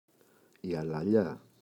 αλαλιά, η [alaꞋʎa] – ΔΠΗ
αλαλιά-η.mp3